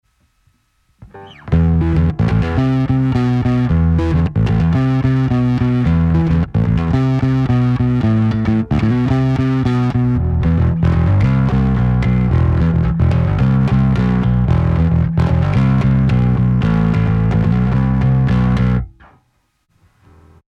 Dies ist ein quasi-Acoustic Lied von 1999.